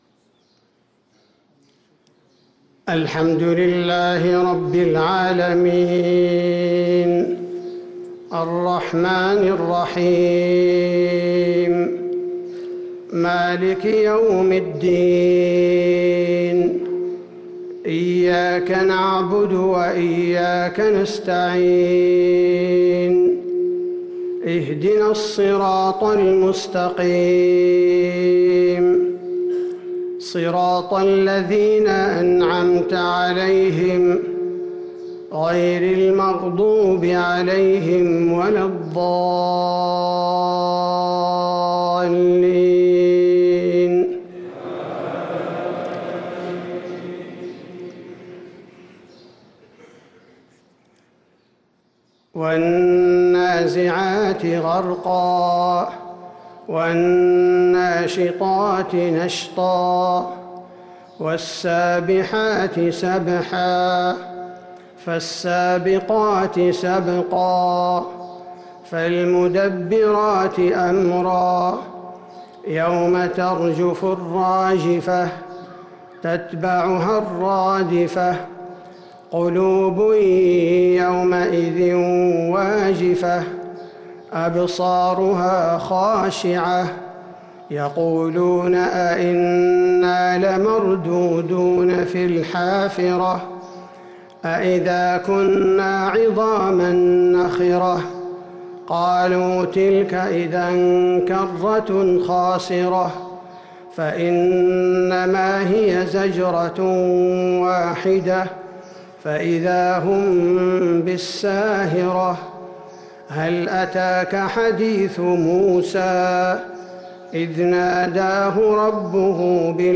صلاة الفجر للقارئ عبدالباري الثبيتي 4 رمضان 1444 هـ
تِلَاوَات الْحَرَمَيْن .